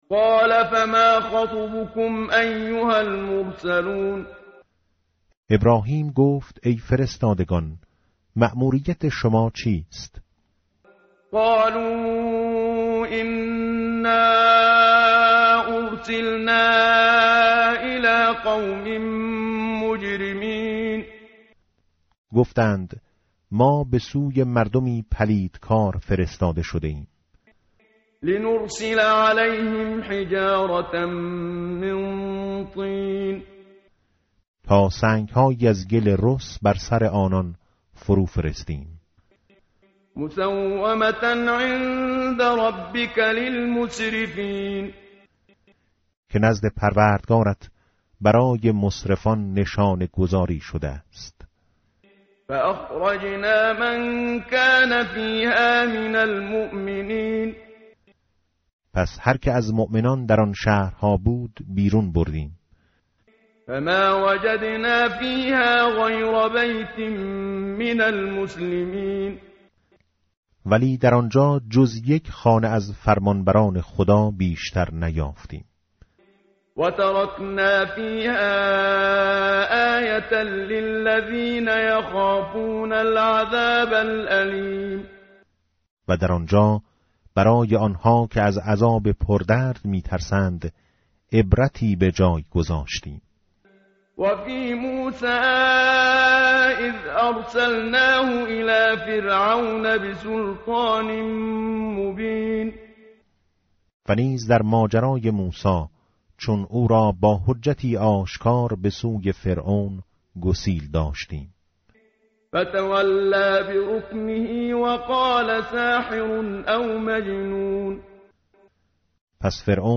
متن قرآن همراه باتلاوت قرآن و ترجمه
tartil_menshavi va tarjome_Page_522.mp3